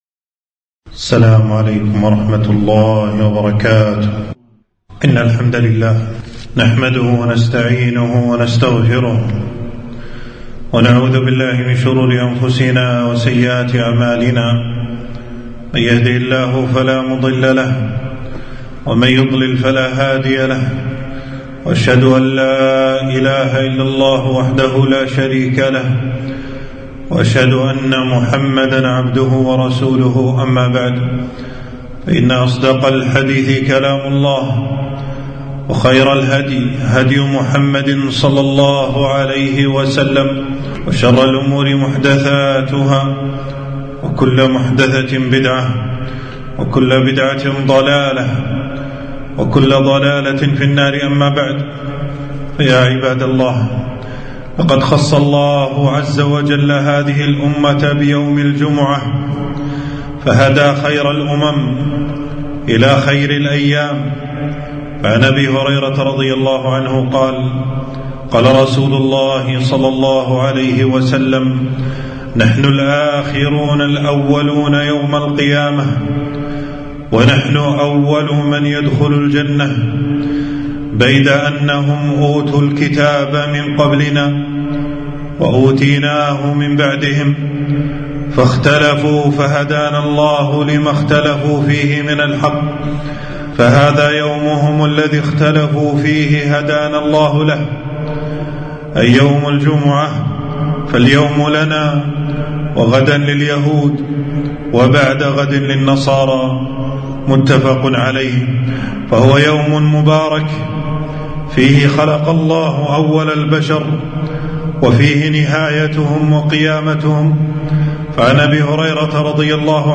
خطبة - فضل صلاة الجمعة وبعض أحكامها